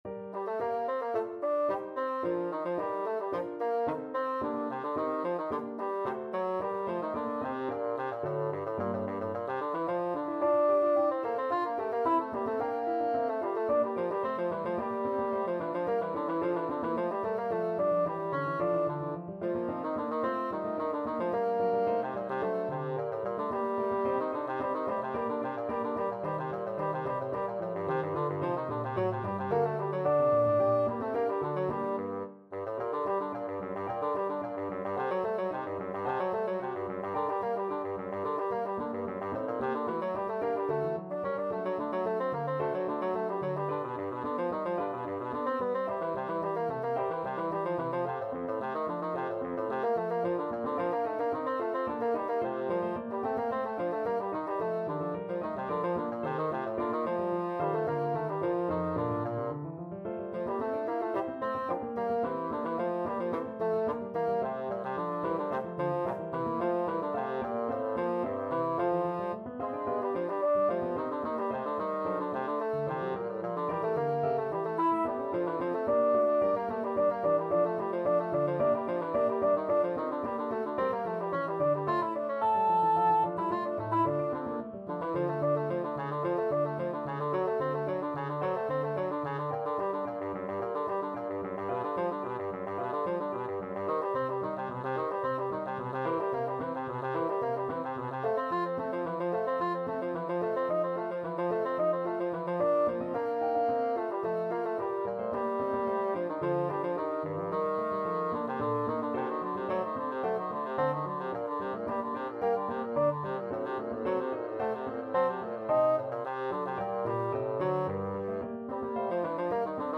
Bassoon
2/2 (View more 2/2 Music)
G minor (Sounding Pitch) (View more G minor Music for Bassoon )
Allegro =110 (View more music marked Allegro)
Classical (View more Classical Bassoon Music)